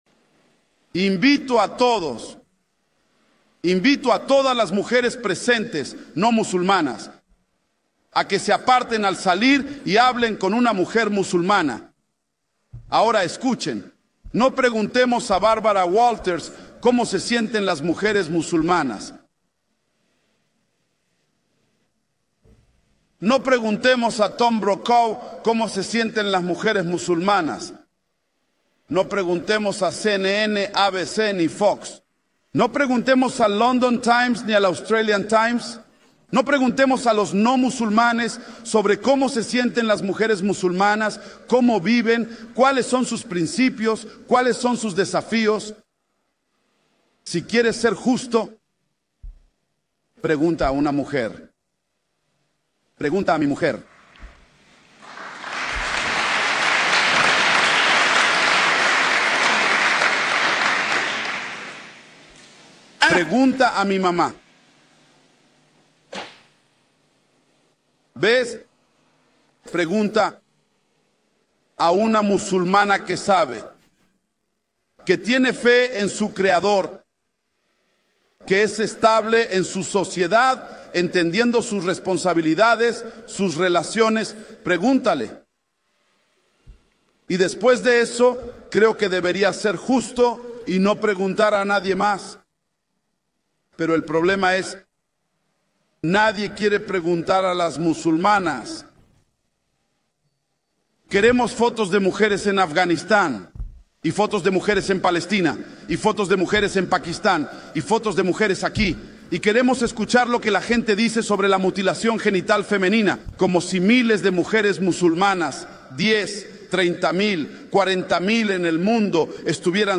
El video es un hermoso clip de una de las conferencias